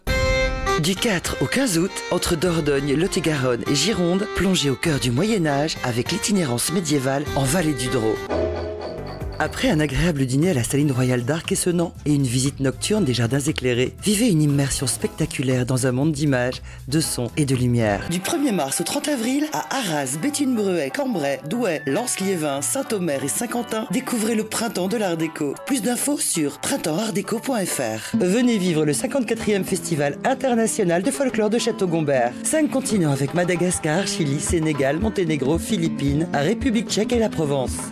La voix, la voix qui prend peu de rides mais garde sa chaleur son empathie son enthousiasme !
Sprechprobe: Werbung (Muttersprache):
Pub Promo.mp3